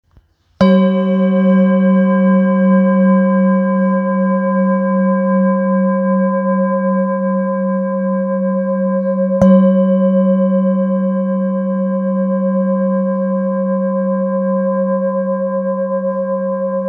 Singing Bowl, Buddhist Hand Beaten, with Fine Etching Carving of Samadhi, Select Accessories
Material Seven Bronze Metal
When played, the bowls produce a rich, harmonious sound that is said to stimulate the chakras and bring balance to the body's energy centers.